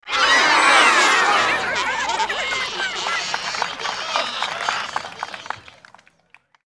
Golf_Crowd_Miss.ogg